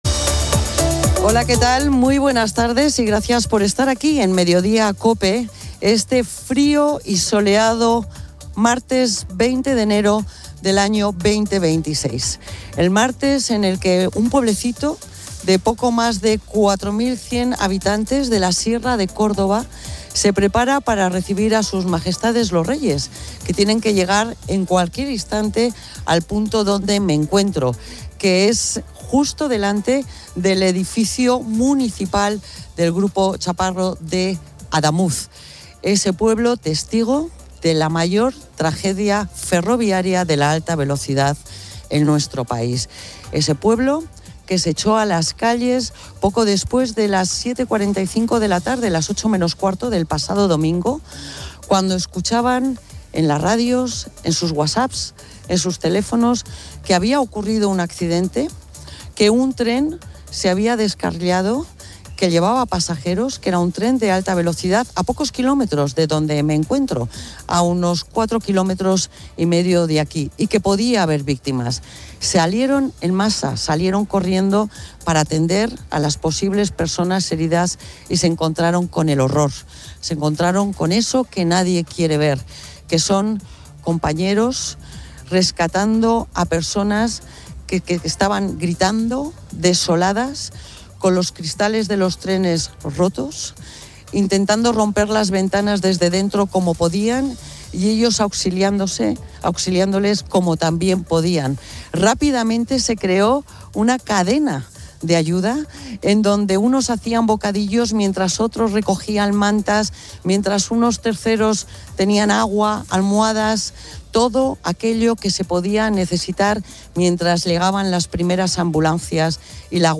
un superviviente, narra la rapidez y el caos del impacto. La Guardia Civil y la Policía recogen pruebas para la investigación, donde se menciona un posible mal punto de soldadura como indicio. Psicólogos de Cruz Roja asisten a las familias en el centro cívico de Córdoba, quienes esperan noticias sobre sus seres queridos.